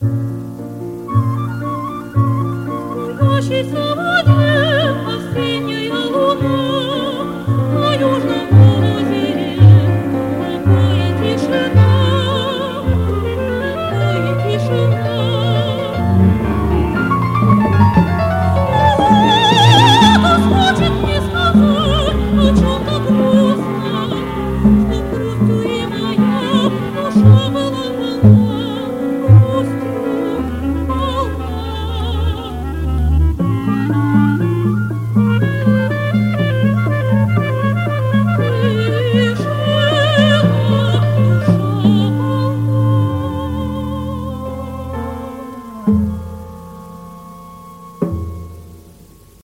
сопрано
флейта
кларнет